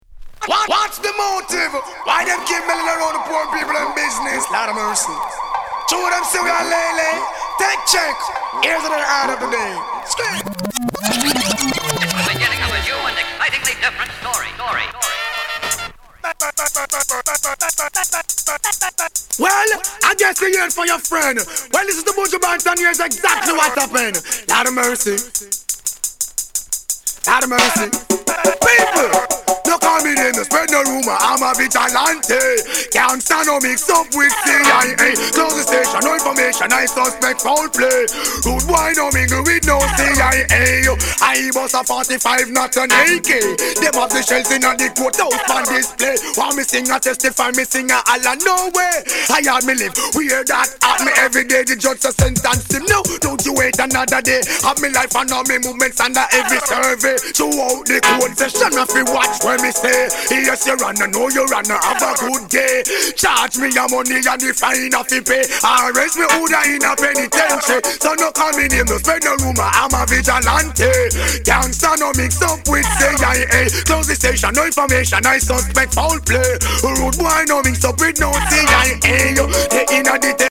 Genre: Reggae / Dancehall